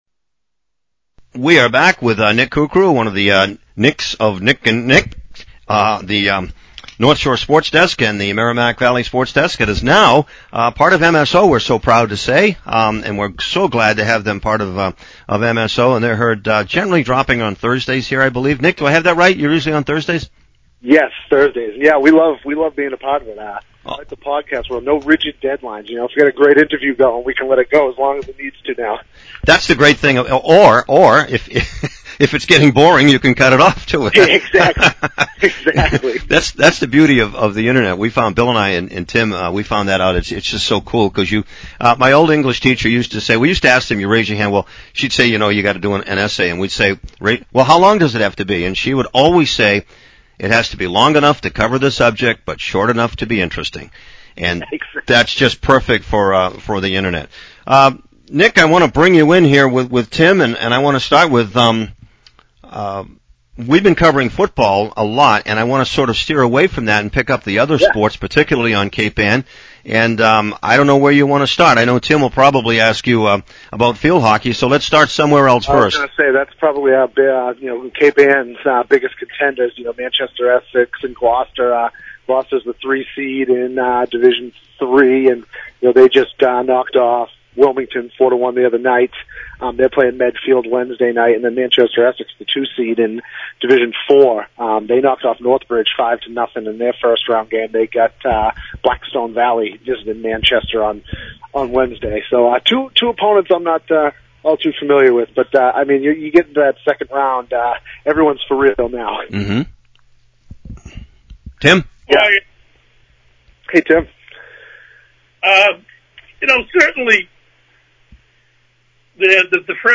(His interview was prior to the start of a Rockport-KIPP soccer playoff game in Lynn.)